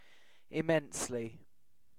Ääntäminen
Synonyymit enormously vastly Ääntäminen UK Haettu sana löytyi näillä lähdekielillä: englanti Käännöksiä ei löytynyt valitulle kohdekielelle.